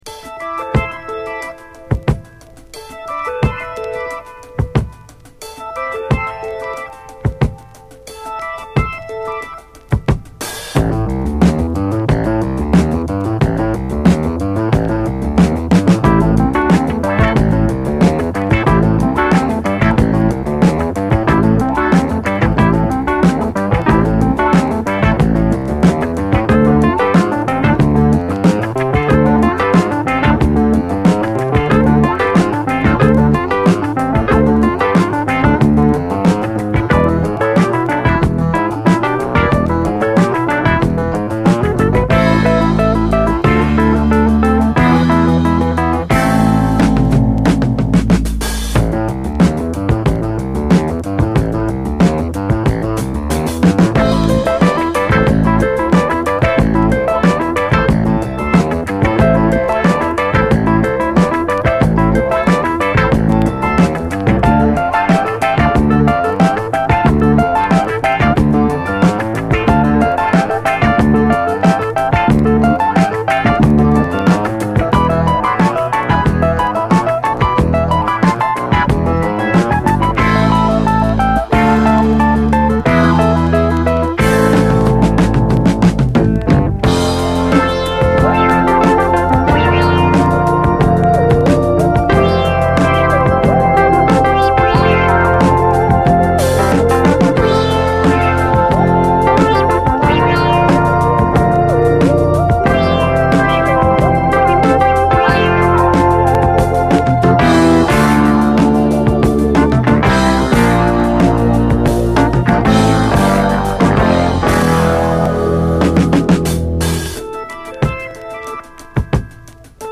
JAZZ FUNK / SOUL JAZZ, JAZZ
カントリー界のピアニストによる異色作！
中盤のギター・リフ部が熱い。